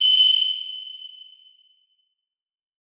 Звуки сонара
Звук сонара НЛО в космическом пространстве